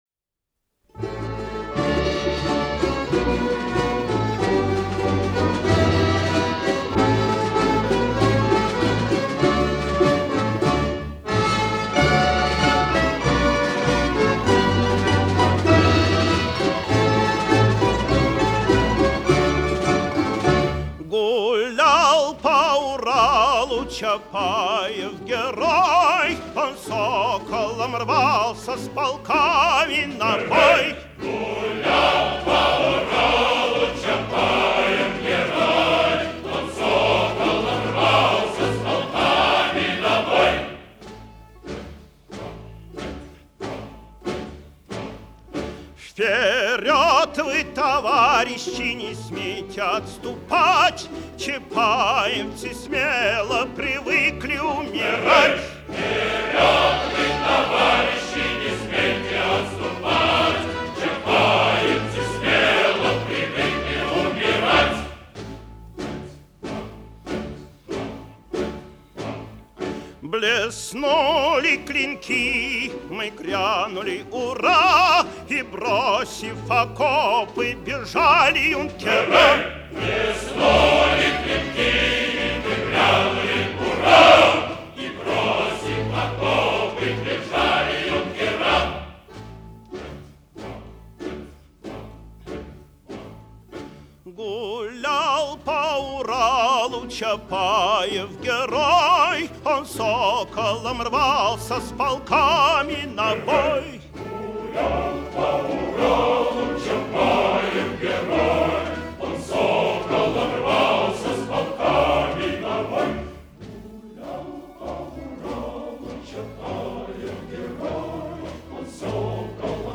Исполняет: Ансамбль Советской песни